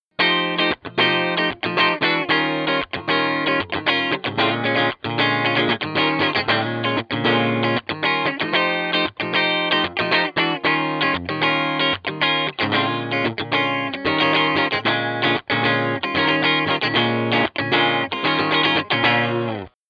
Fane Ascension® Series N30. 12-Inch, 30 watt guitar loudspeaker with Neodymium magnet motor system.
The Ascension N30 captures the complex sonic structure usually associated with an alnico loudspeaker in a light weight neodymium format imbued with Fane's authentic vintage tone.
Displaying crystal clear, clean tones and a fantastic tight crunch when played through distortion.
N30-Funk-192kbps.mp3